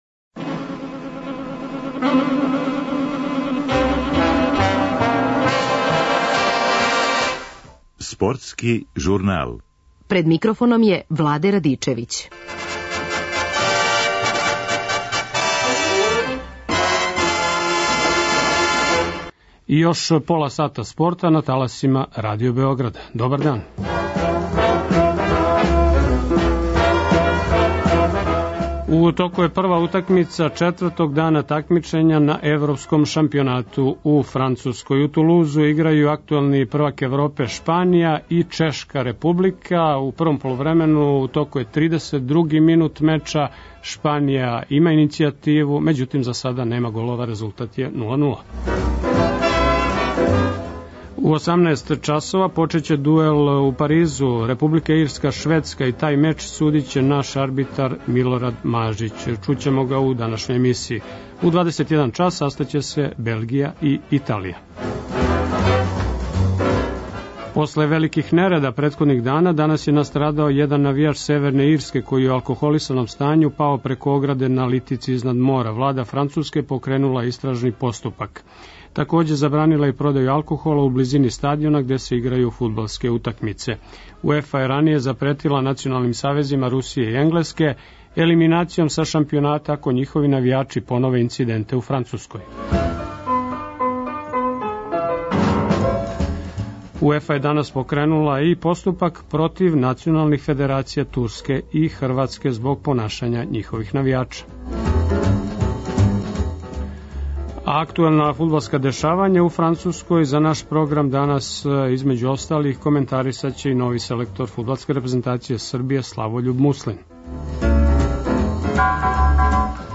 Анализираћемо и јучерашње резултате, а наши саговорници су бивши фудбалери и тренери.